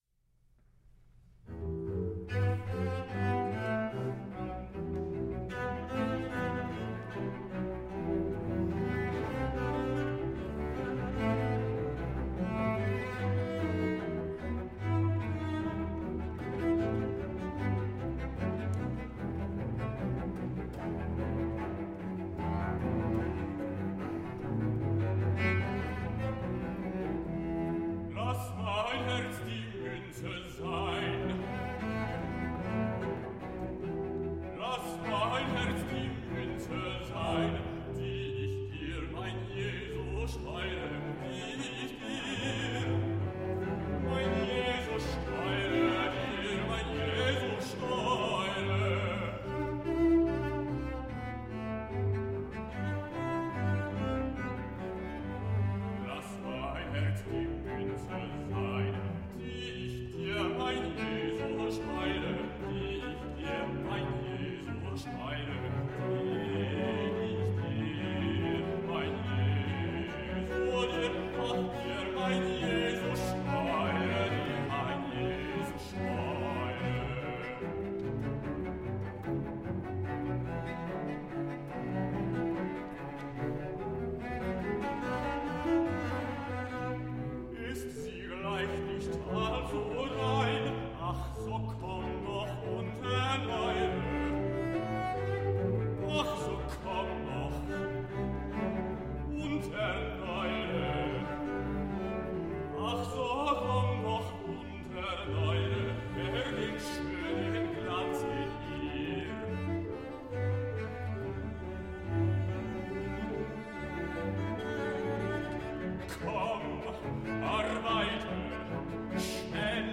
cellos
continuo